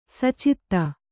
♪ sacitta